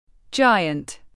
Giant /ˈdʒaɪənt/